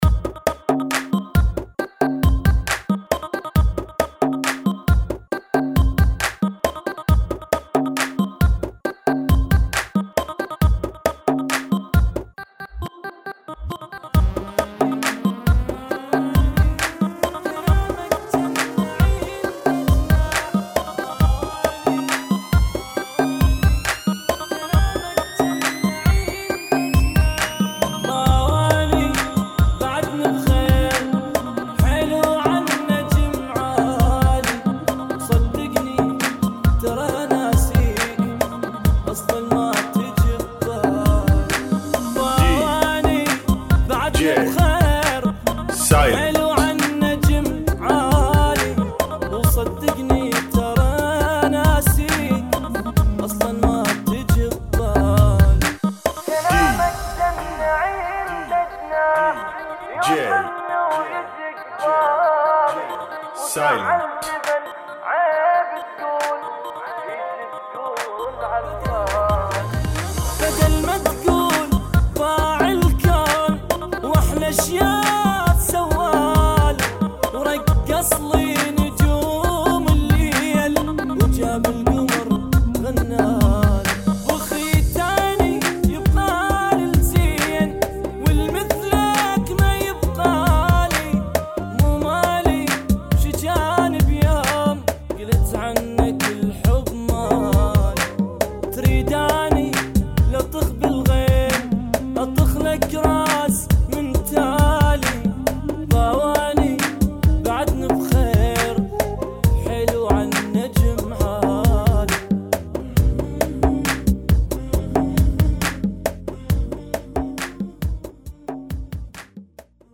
[ 98 BPM ]